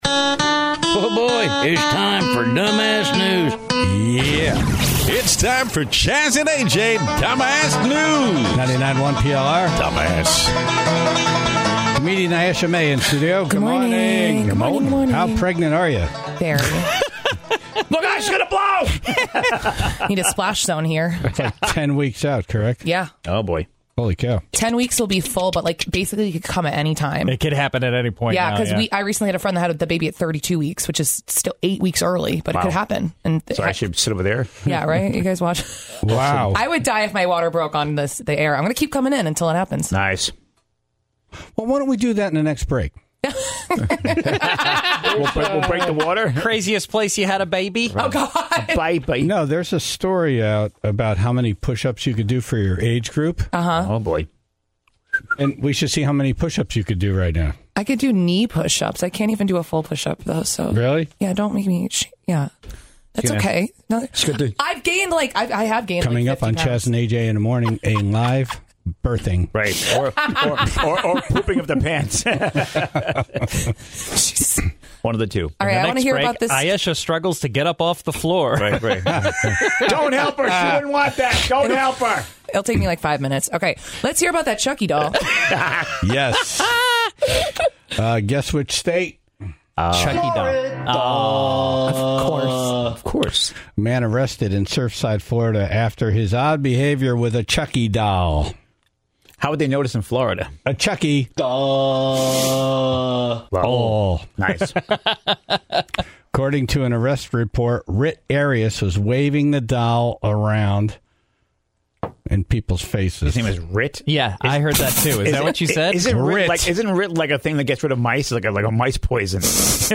The Tribe called in to share their favorite moments from Toad's Place shows they've attended over the last 50 years. (21:58) In Dumb Ass News, a man attempted a Guinness World Record by chewing on burning coal embers.